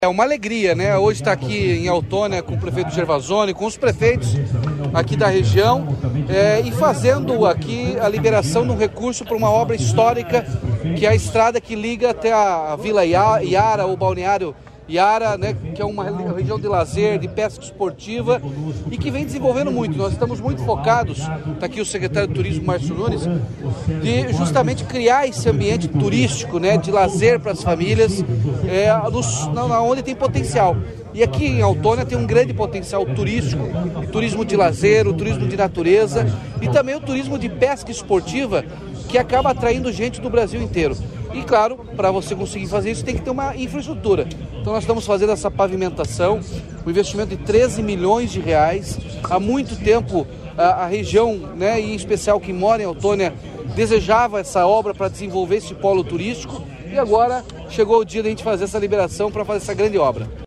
Sonora do governador Ratinho Junior sobre o investimento de R$ 13,7 milhões para pavimentação de uma estrada em Altônia